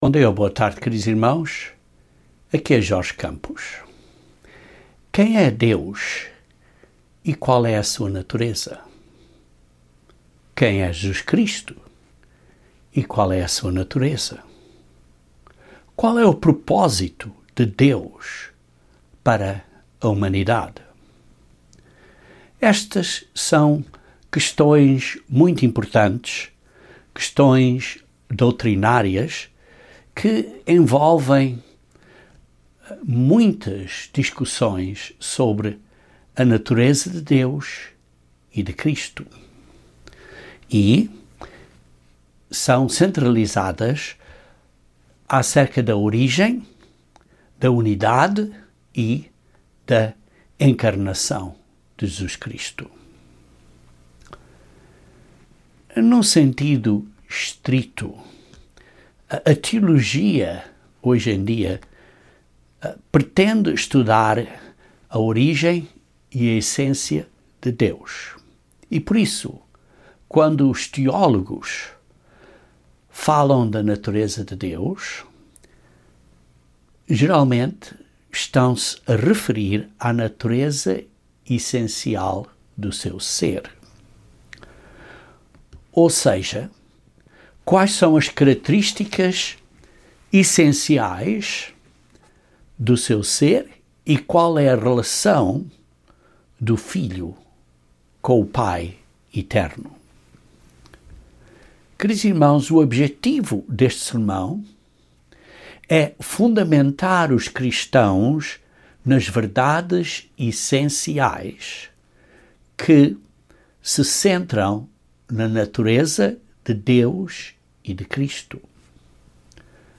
O objectivo deste sermão é fundamentar os cristãos nas verdades essenciais que se centram na natureza de Deus e de Cristo, pois é nessas verdades essenciais que o maravilhoso propósito de Deus para a humanidade é revelado.